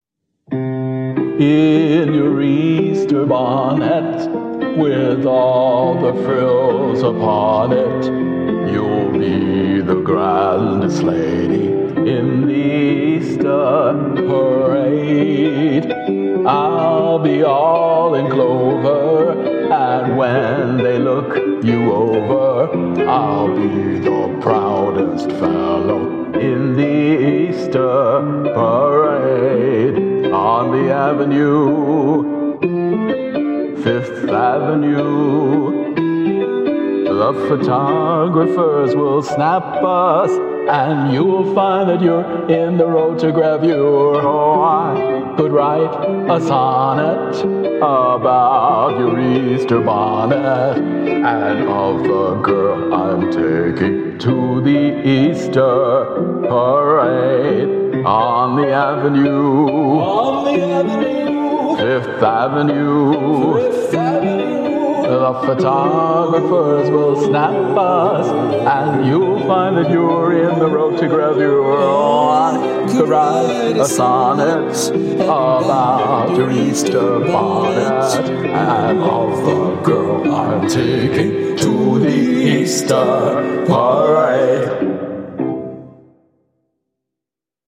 vocals
piano
ragtime piano